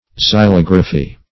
Search Result for " xylography" : The Collaborative International Dictionary of English v.0.48: Xylography \Xy*log"ra*phy\, n. [Xylo- + -graphy: cf. F. xylographie.]